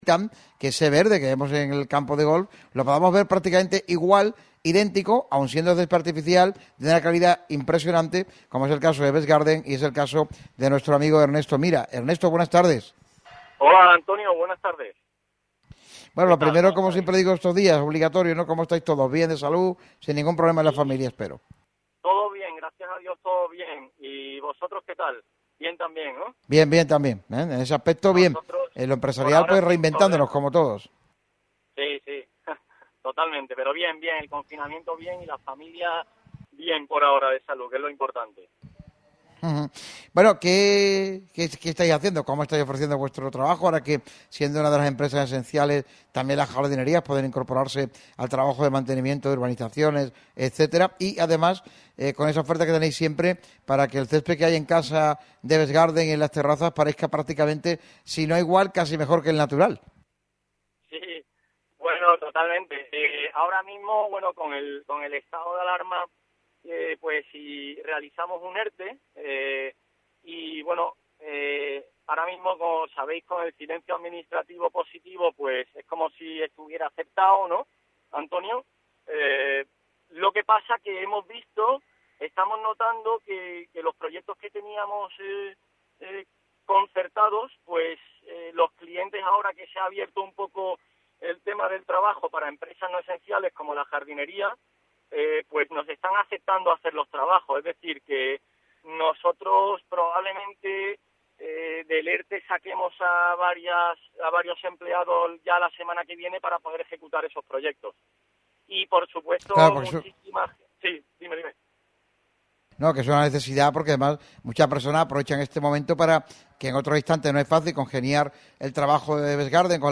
atendió telefónicamente a la llamada de Radio Marca Málaga para dialogar sobre la calidad del césped artificial y la sustitución de este por el natural además de cómo están trabajando en estos delicados momentos.